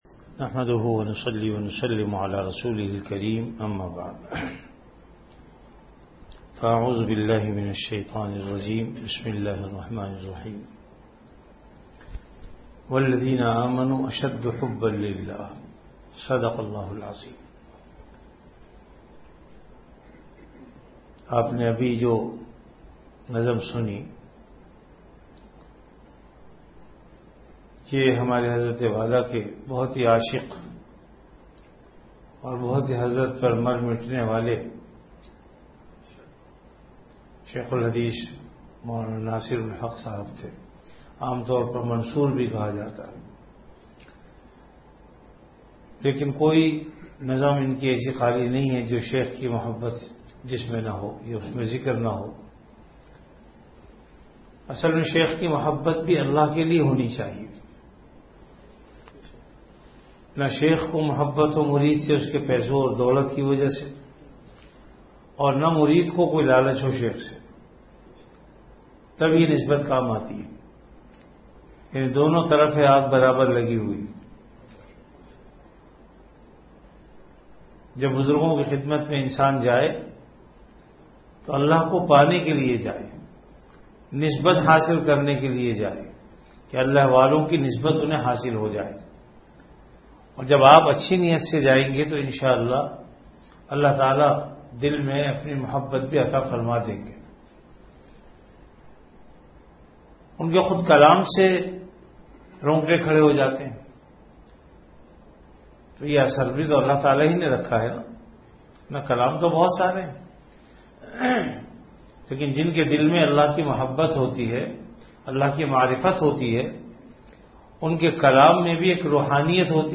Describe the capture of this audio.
Majlis-e-Zikr · Jamia Masjid Bait-ul-Mukkaram, Karachi